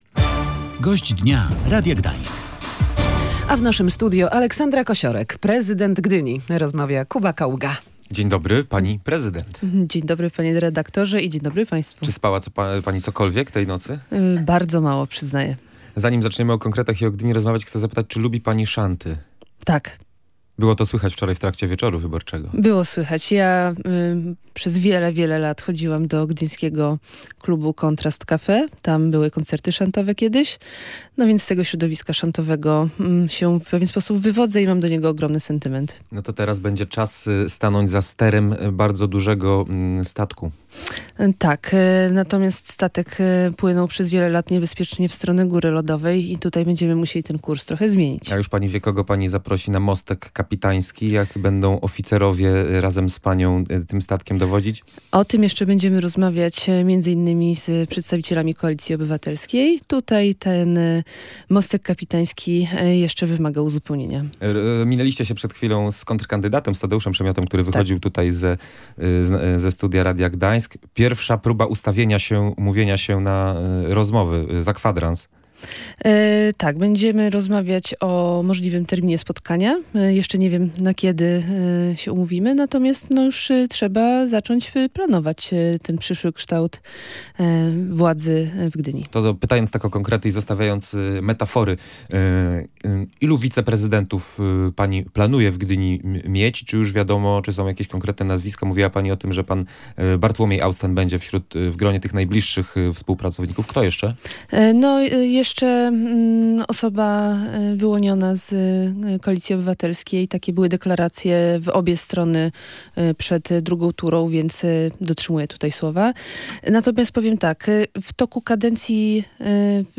Gdyński Dialog i Koalicja Obywatelska będą ze sobą współpracować w Radzie Miasta Gdyni – zadeklarowała w audycji „Gość Dnia Radia Gdańsk” nowa prezydent miasta Aleksandra Kosiorek. Nie wiadomo jednak, czy oba kluby zawiążą formalną koalicję.